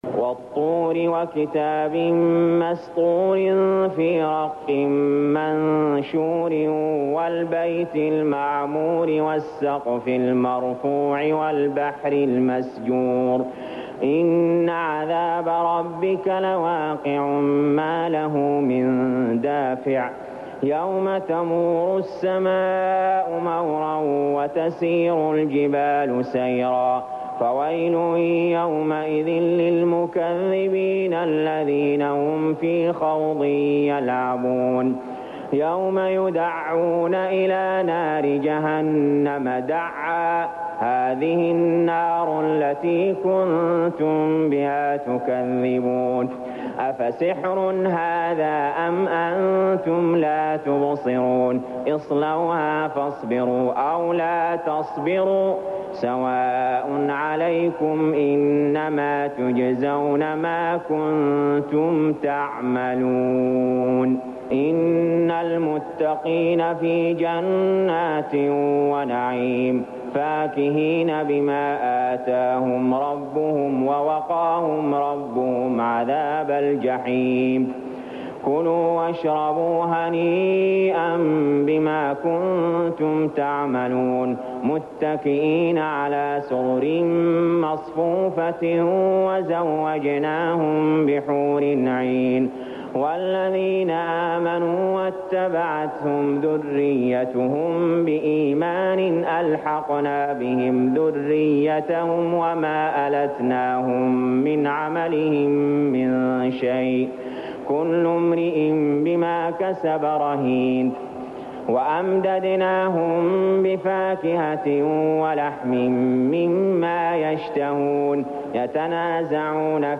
المكان: المسجد الحرام الشيخ: علي جابر رحمه الله علي جابر رحمه الله الطور